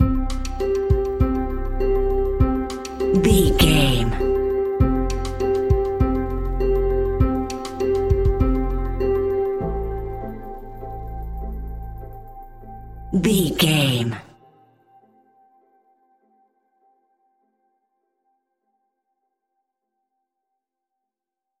Thriller
Ionian/Major
ominous
dark
haunting
eerie
synthesizer
drum machine
horror music